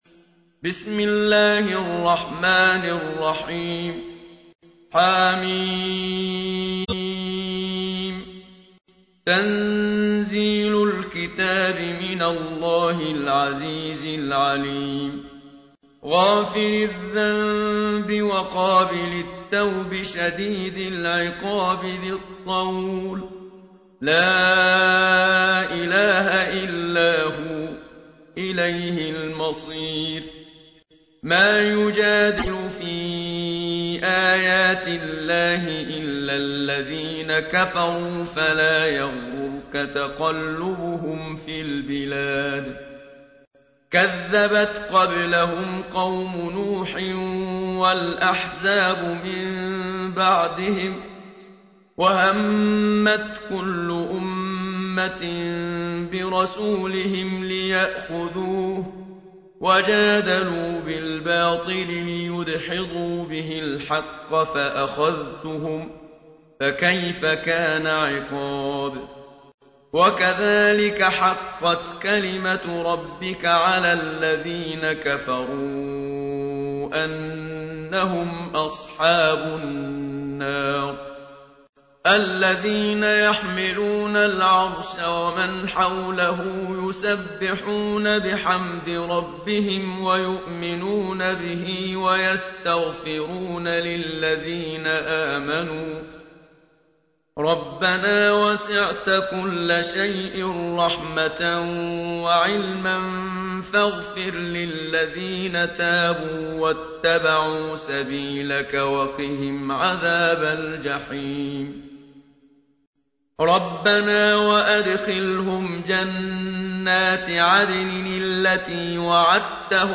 تحميل : 40. سورة غافر / القارئ محمد صديق المنشاوي / القرآن الكريم / موقع يا حسين